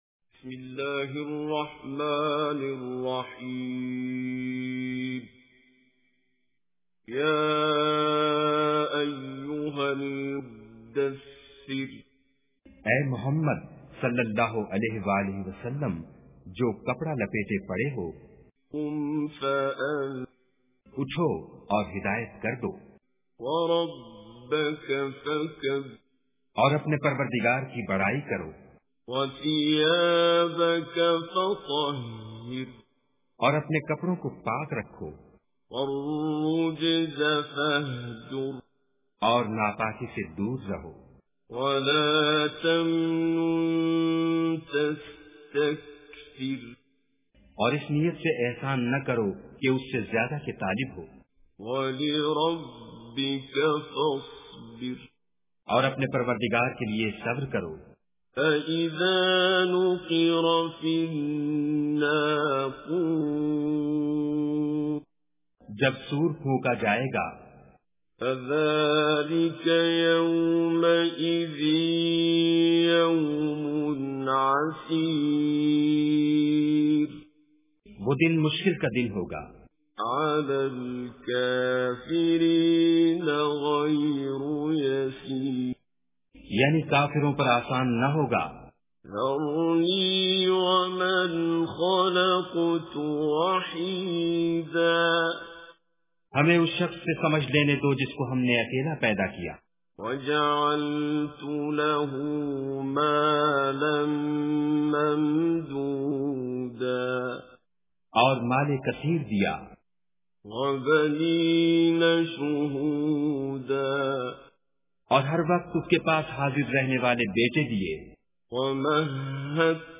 Surah Mudassir Recitation with Urdu Translation
surah-mudassir.mp3